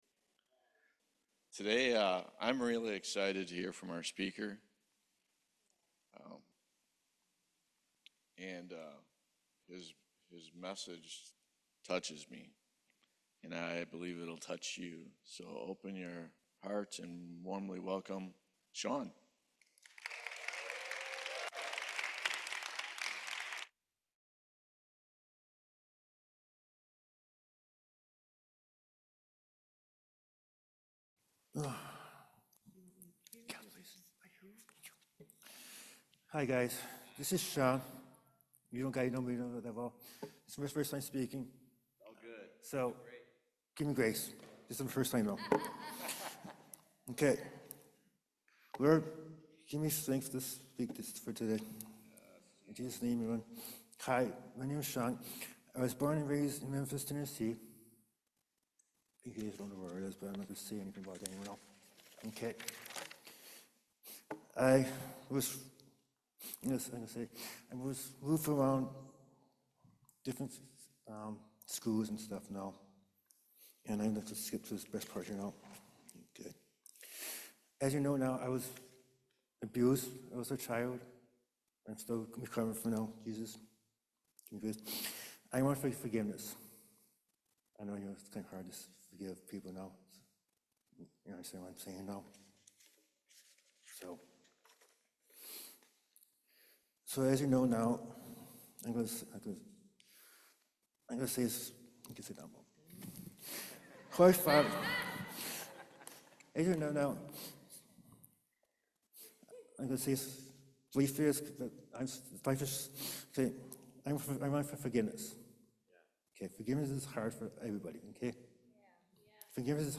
Testimony Passage: Ephesians 4:32 Service Type: Main Service God understands me.